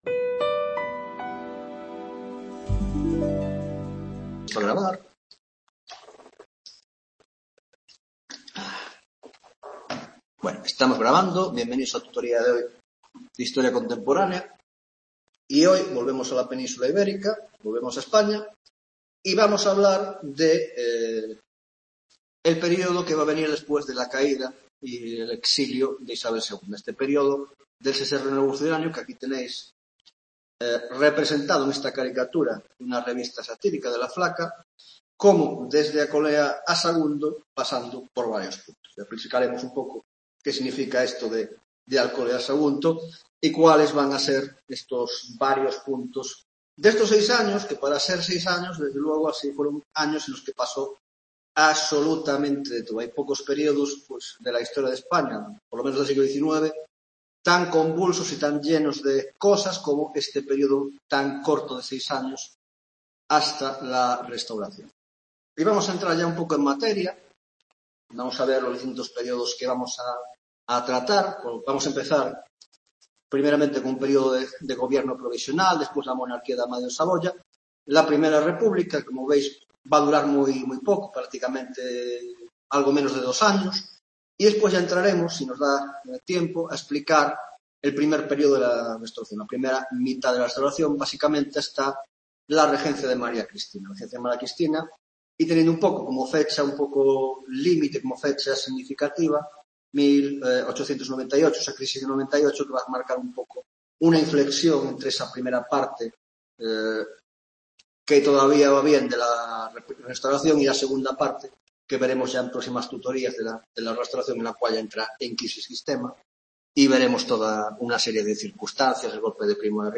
7ª tutoría de Historia Contemporánea - Del Sexenio Democrático a la Restauración (1ª parte)